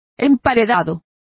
Complete with pronunciation of the translation of sandwich.